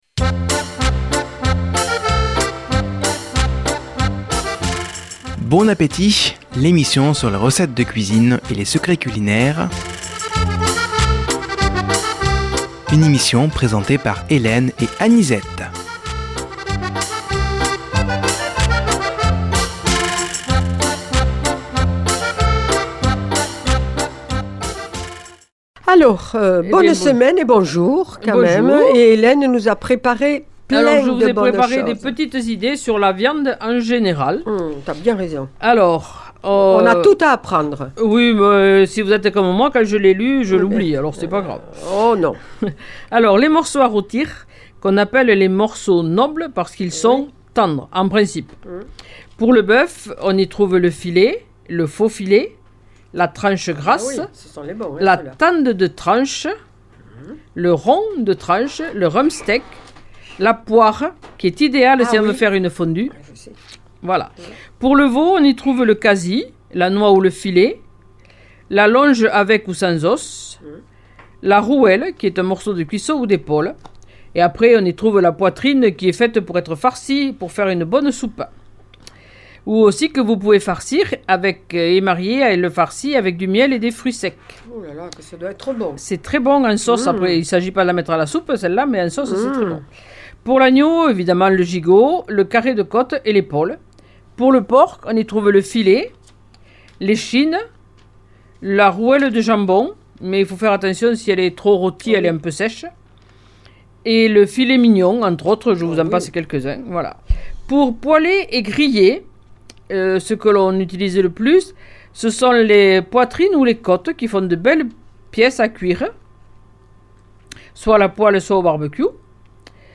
Une émission présentée par
Présentatrices